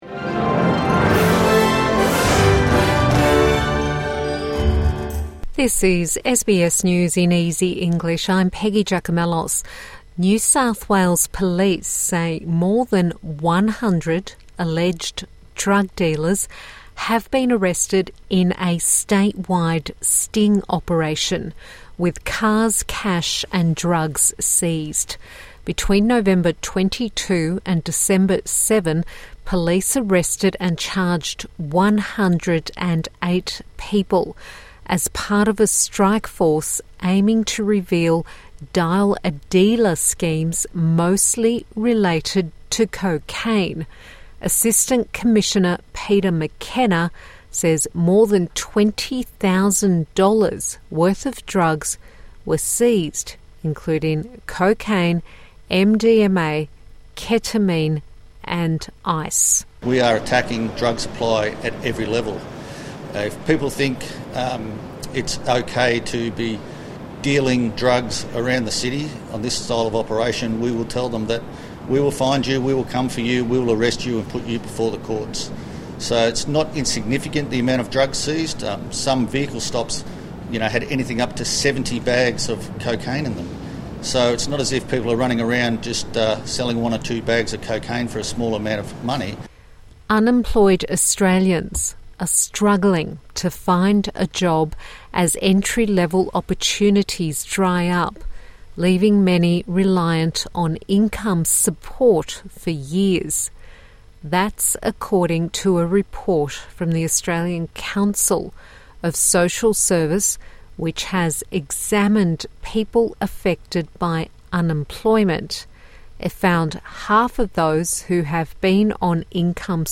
A five minute bulletin for English language learners